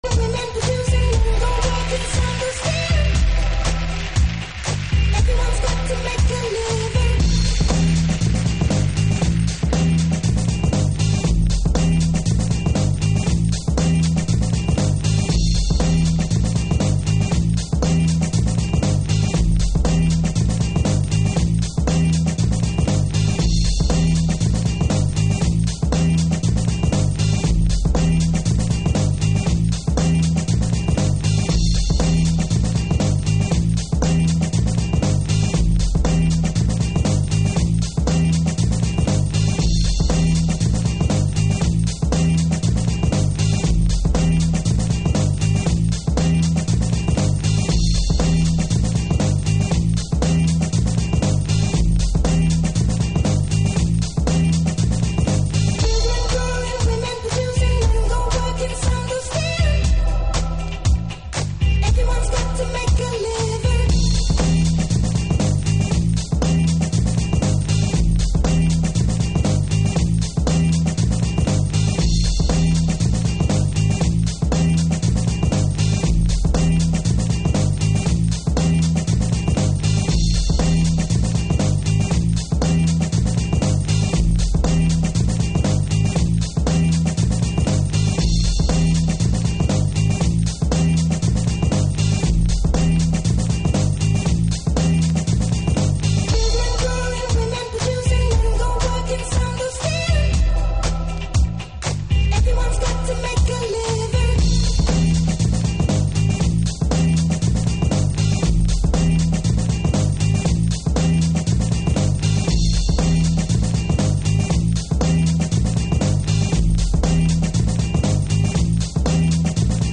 Early House / 90's Techno
片面プレスで溝が深い、もちろん音が太い。